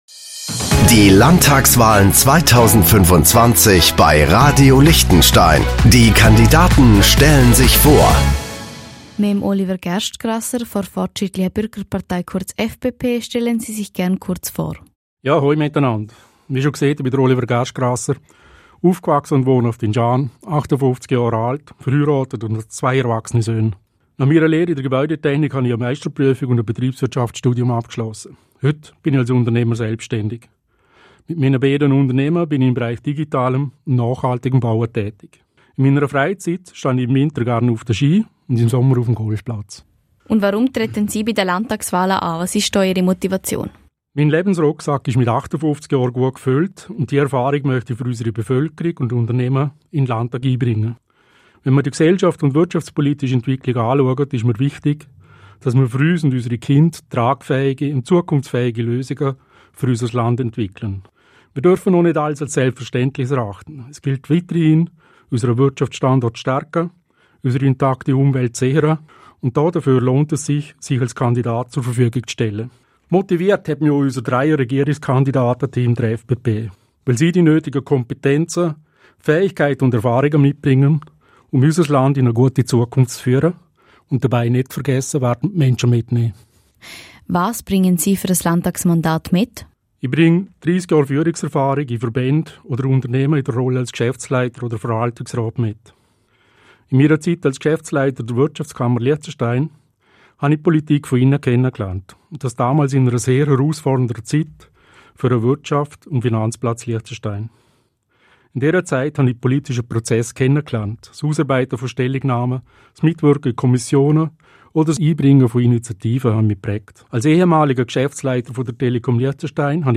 Landtagskandidat